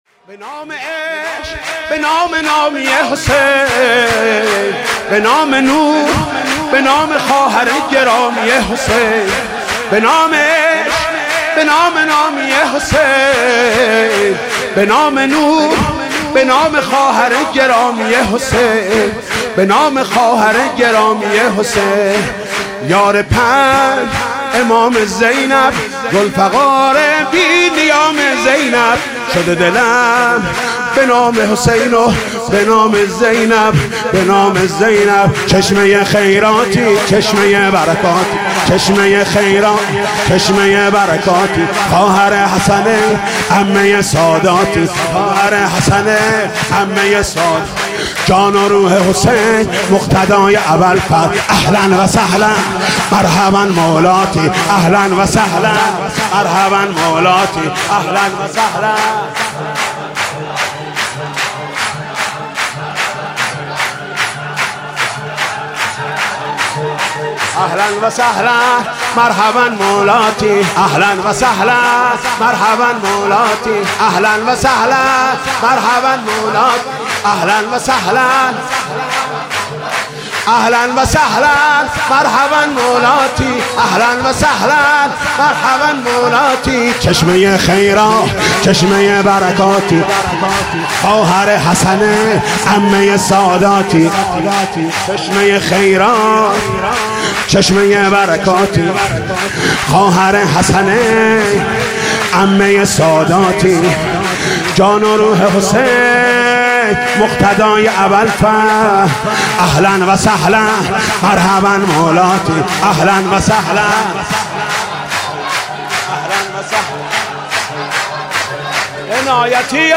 خیمه گاه - عاشقان اهل بیت - سال97- سرود- به نام عشق به نام نامی حسین- حاج محمود کریمی